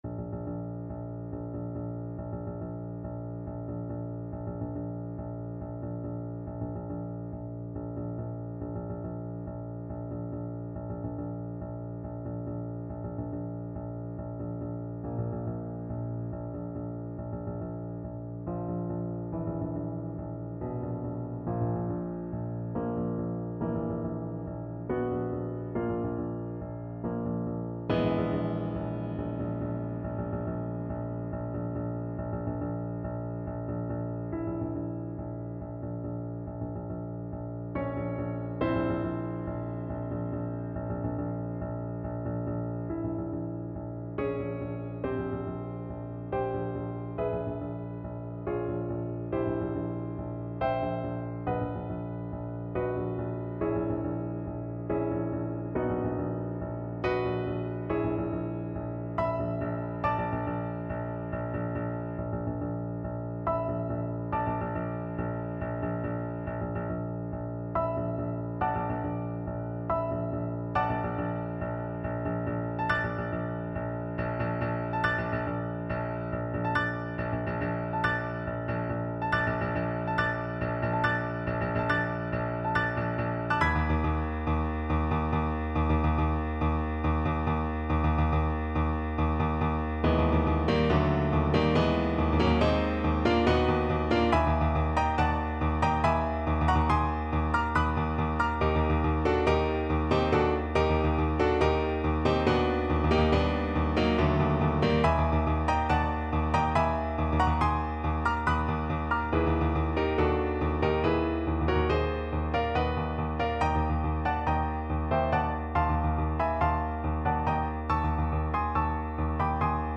5/4 (View more 5/4 Music)
Allegro = 140 (View more music marked Allegro)
Classical (View more Classical Clarinet Music)